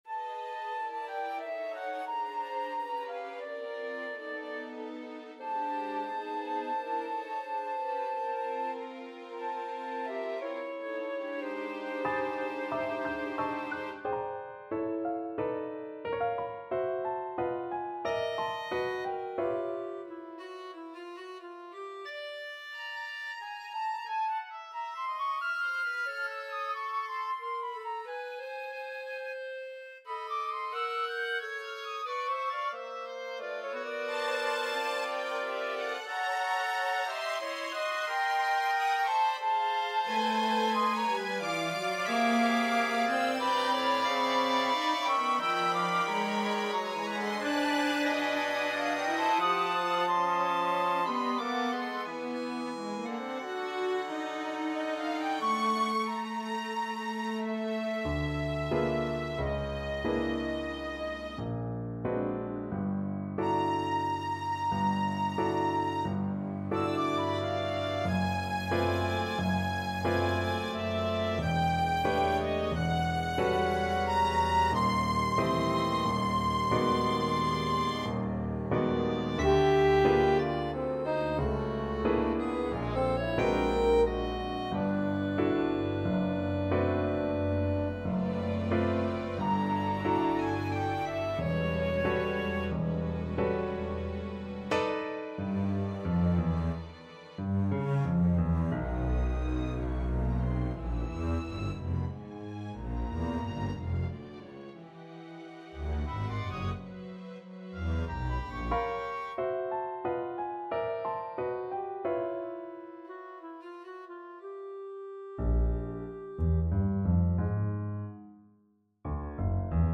The following are are some of my compositions realized in midi.
An atonal chamber orchestra piece (which needs spoken narration) called The Little Bird that Fell: